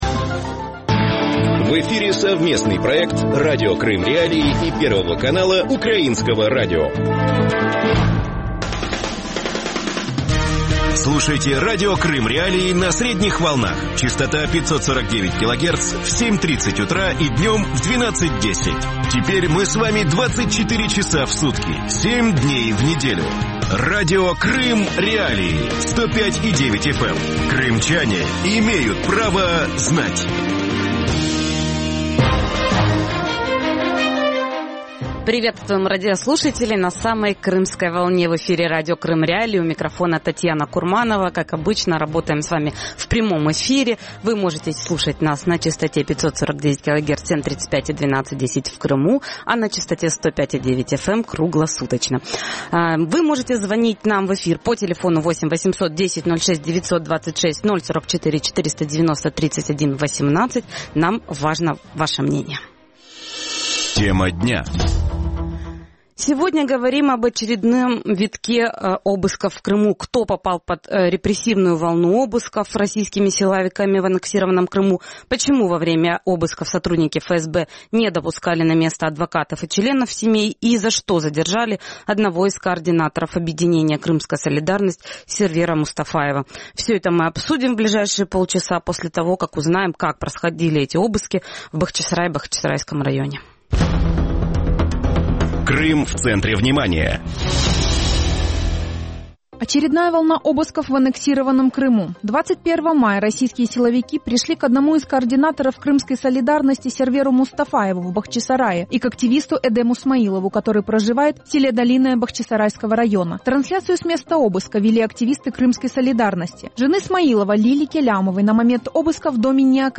Гости эфира